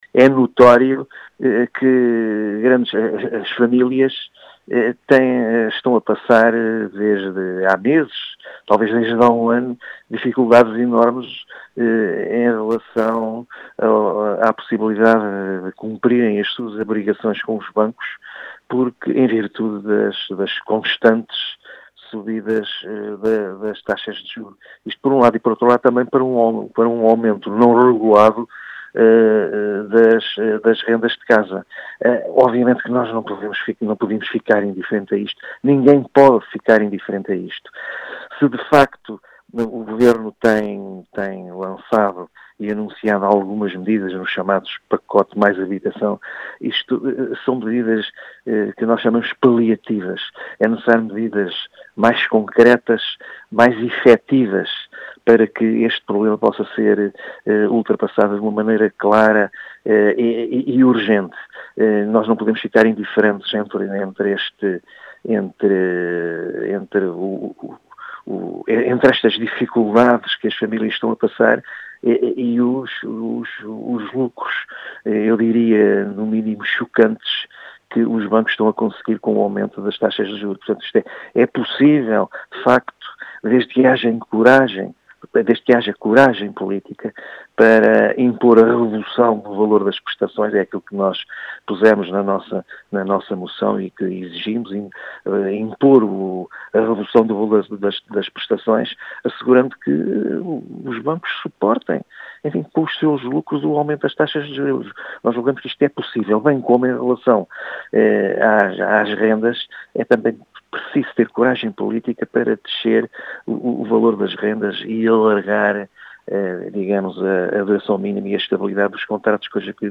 As explicações são de Bernardo Loff, eleito da CDU na Assembleia municipal de Beja.